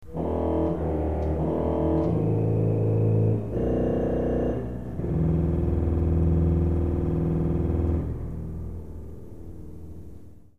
Bombarde
Organowy głos językowy z grupy trąbek o węższej od nich menzurze, występuje w wersji 32' lub 16' w manuale, częściej w pedale. Nazwa pochodzi od dawnego instrumentu po polsku zwanego pomortem, będącego pod względem konstrukcji poprzednikiem fagotu, a brzmiącego podobnie do dzisiejszej tuby.
bombarde32_z_okatawa8.mp3